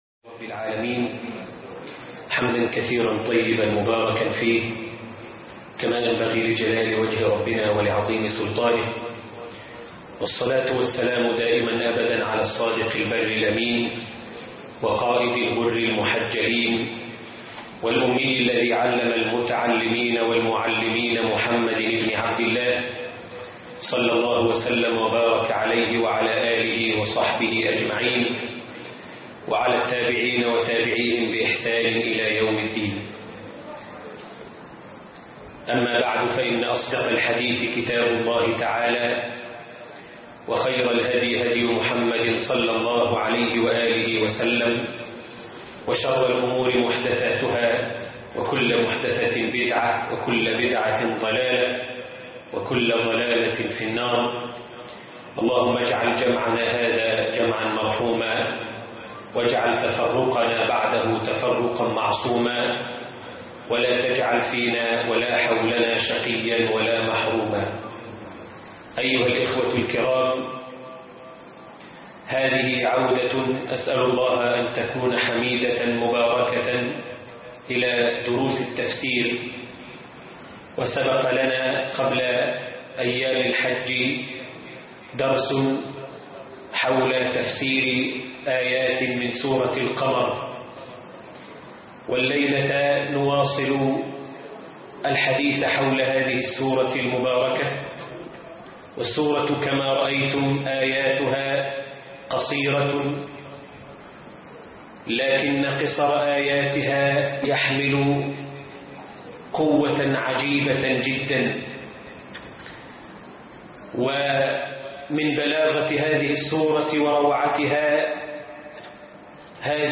قصص وعبر من سورة القمر - دروس مسجد قباء بالهرم